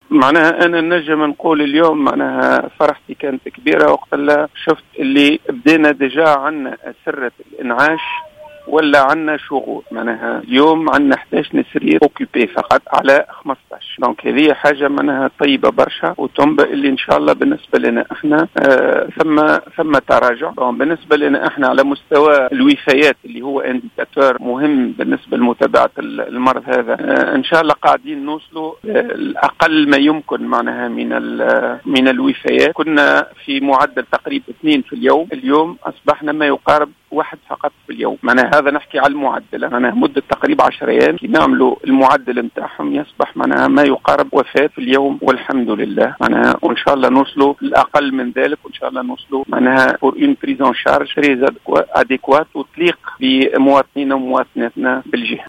أكد المُدير الجهوي للصحة بالمنستير حمودة الببة، في تصريح للجوهرة أف أم، اليوم الثلاثاء، تحسّن الوضع الوبائي في الجهة، مع تسجيل شغور في أسرّة الإنعاش (4 أسرة من جملة 15)، واستقرار أعداد الوفيات الناجمة عن فيروس كورونا المسّتجد، لتنزل إلى معدل حالة وفاة واحدة يومّياً.